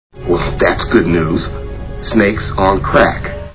Snakes on a Plane Movie Sound Bites